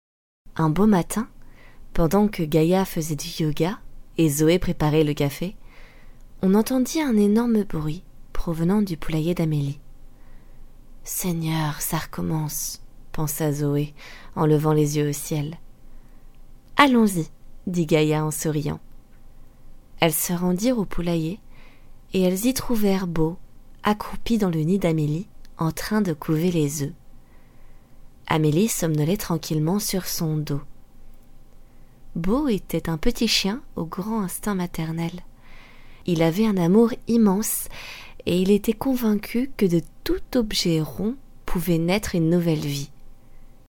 特点：大气浑厚 稳重磁性 激情力度 成熟厚重
风格:浑厚配音